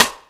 Track 14 - Rim Shot OS.wav